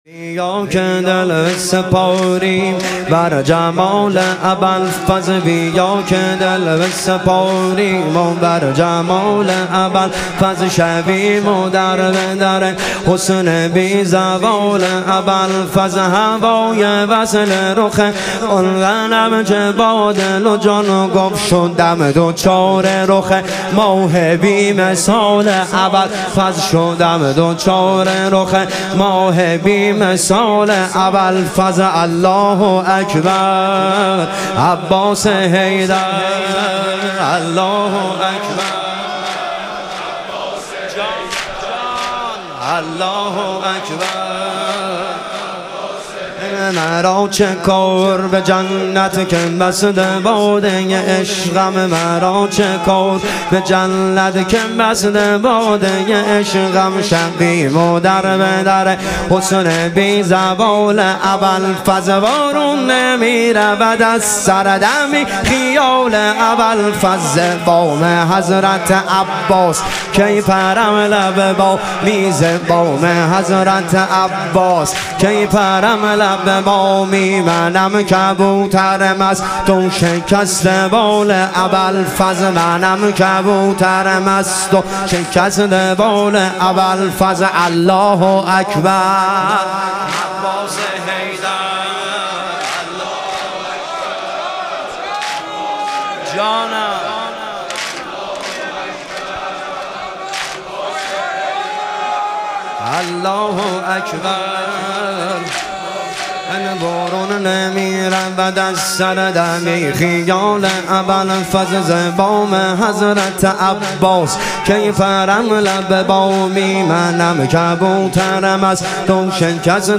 شهادت امام هادی علیه السلام - واحد